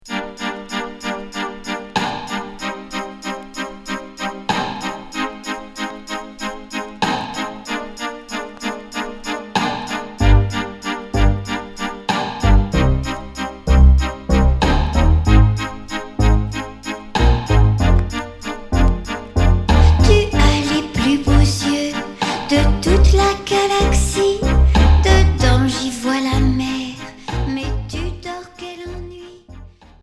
Pop cold wave Troisième 45t retour à l'accueil